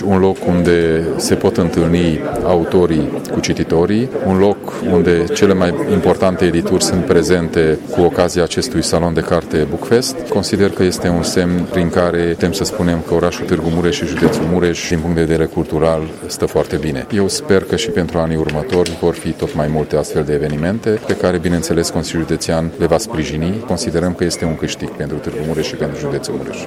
Președintele Consiliului Județean Mureș, Peter Ferenc, a arătat că organizarea unor asemenea evenimente „este semn că județul Mureș a intrat pe drumul cel bun în domeniul culturii”: